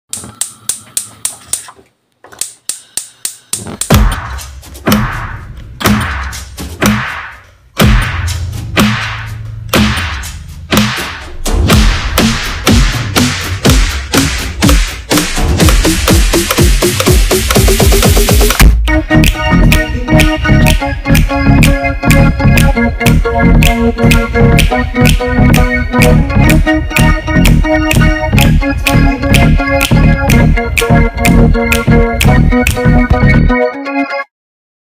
Alcuni degli audio prodotti nel laboratorio di registrazione sonora: Registriamo il nostro ambiente
Suoni della casa:
Suoni-della-casa.mp3